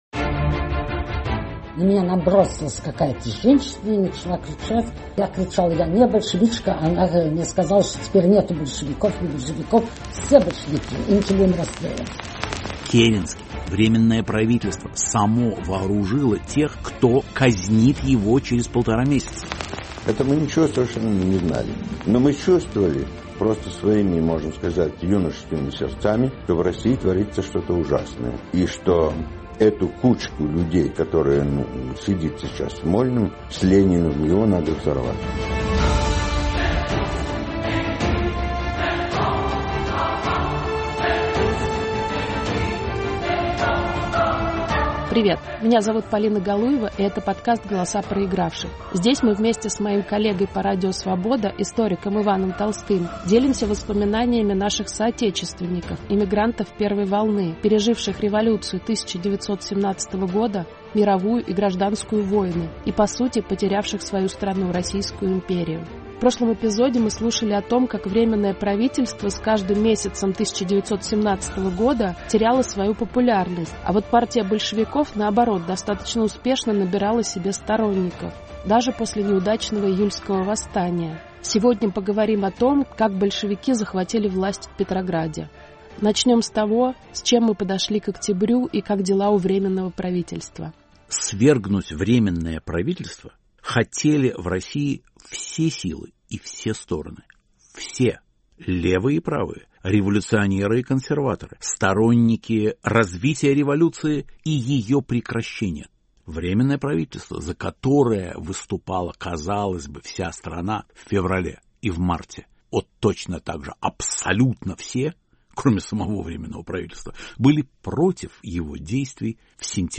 Очевидцы о днях Октябрьской революции